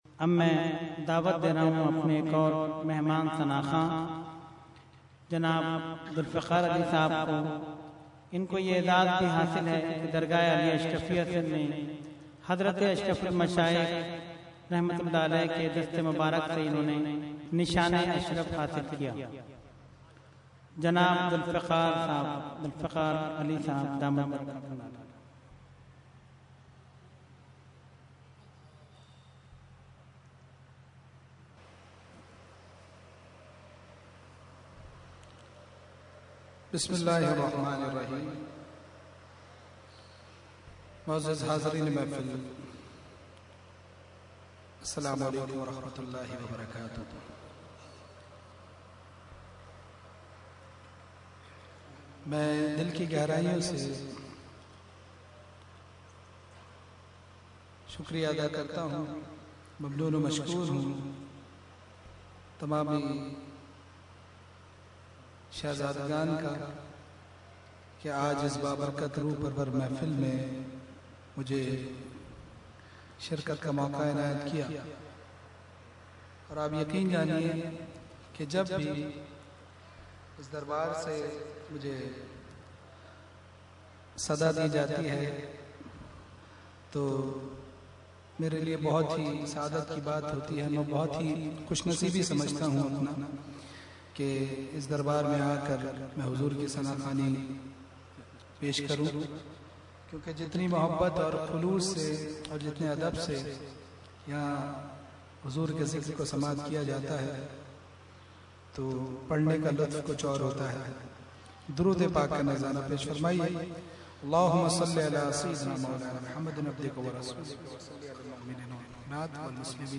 Category : Naat | Language : UrduEvent : Urs Ashraful Mashaikh 2012